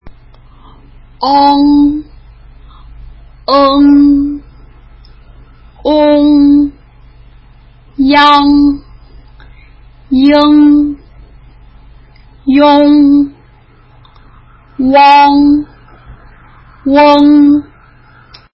【母音＋ng】舌先を口の中のどこにも当てずに口をあけ、息を鼻から出しながら発音するイメージ。
ang 口を開けたまま｢アーン｣と発音するイメージ。
eng 口を軽く開いて鼻から息を漏らす様に｢オーン｣と発音するイメージ。
ong 唇を丸く突き出し、｢オーン｣と発音するイメージ。
ing 口を軽く開け｢イュン｣と発音するイメージ。